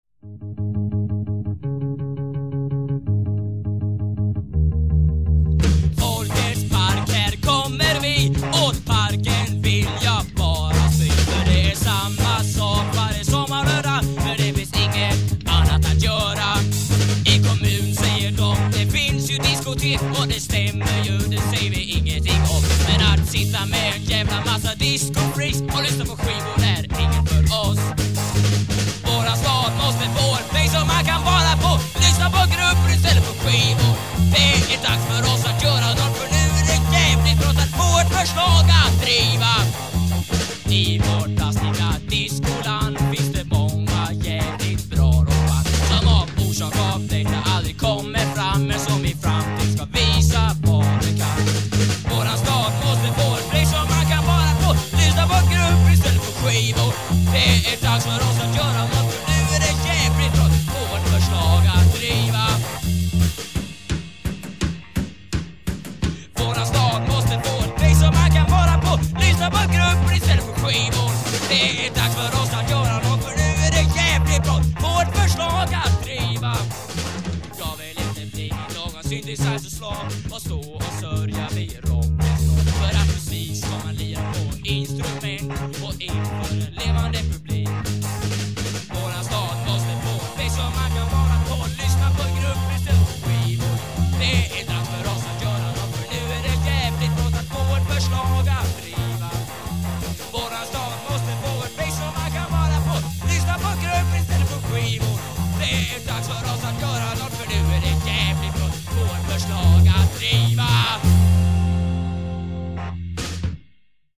Guitar
Voice
Bass
Drums
Keyboards
Recorded in Blästadgården and at some basements in Vidingsjö